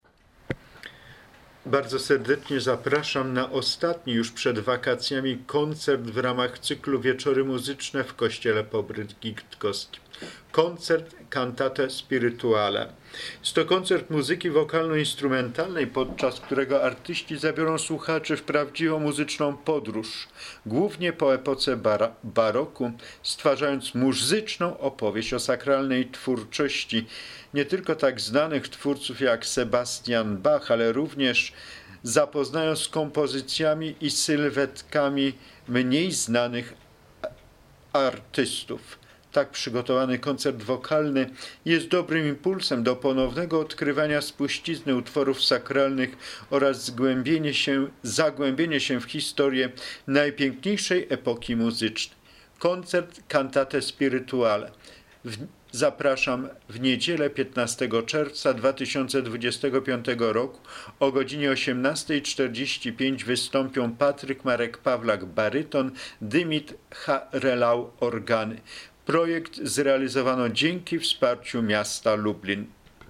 wiolonczela
fortepian
Śpiewność i barwa wiolonczeli jest często porównywana do ludzkiego głosu, a utwory odtworzone za pomocą smyczka przypominają modlitwę. Sakralne wnętrze kościoła oraz niepowtarzalna akustyka z pewnością pozwoli słuchaczowi nie tylko zanurzyć się w piękno muzyki, lecz także w medytację modlitewną.